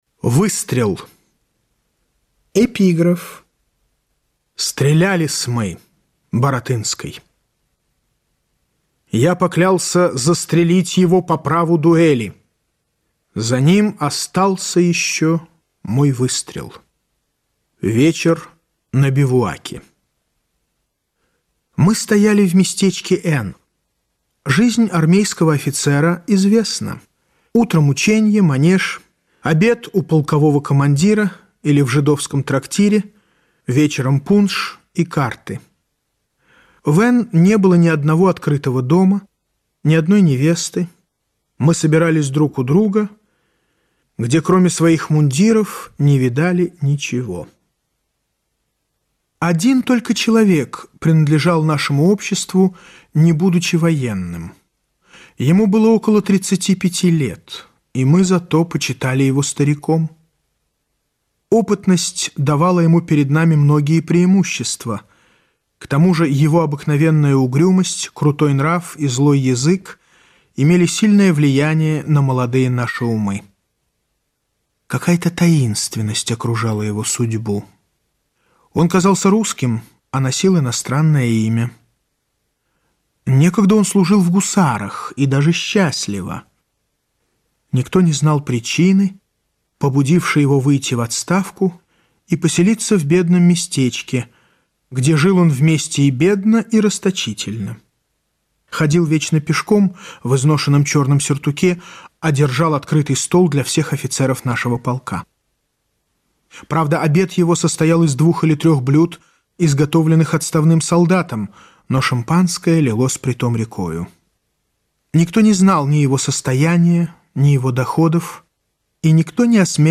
Выстрел - аудио повесть Пушкина - слушать онлайн